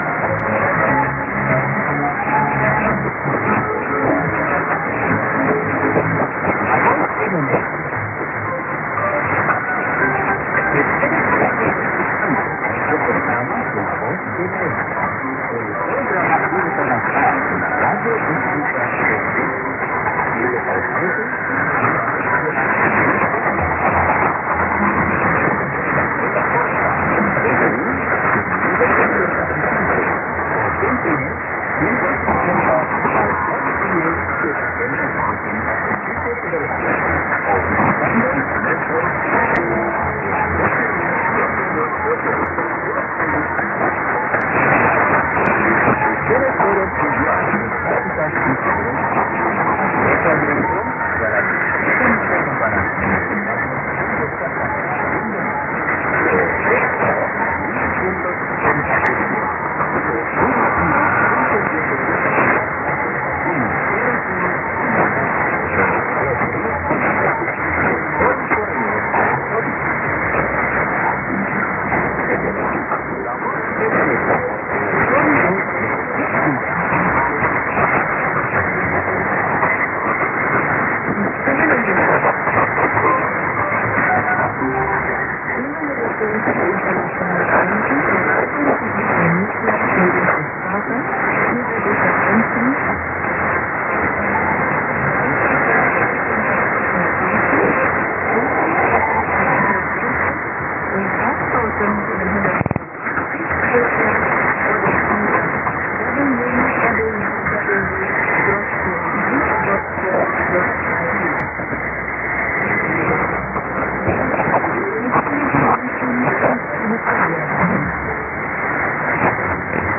・このＨＰに載ってい音声(ＩＳとＩＤ等)は、当家(POST No. 488-xxxx)愛知県尾張旭市で受信した物です。
ID: identification announcement